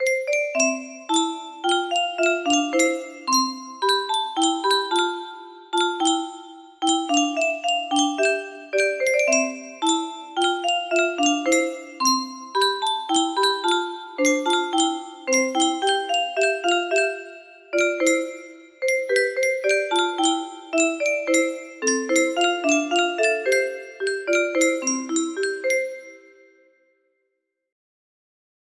Violin loop duet music box melody